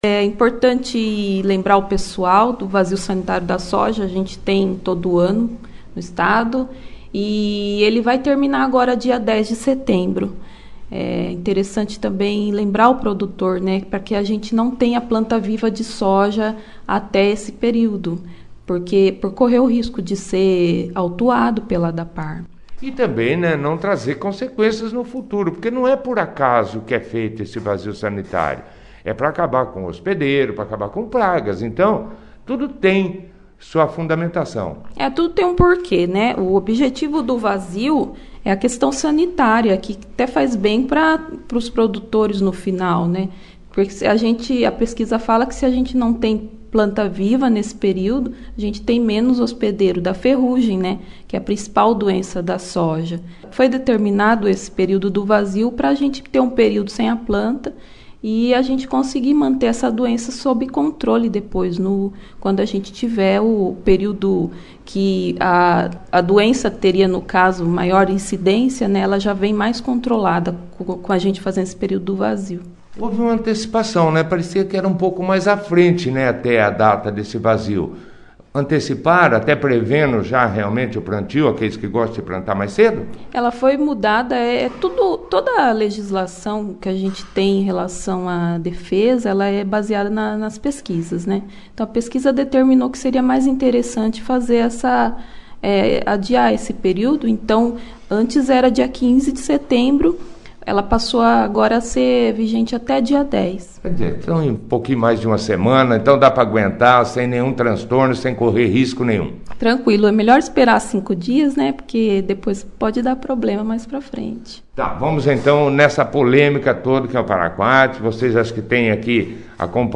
participou da 2ª edição do jornal Operação Cidade desta quarta-feira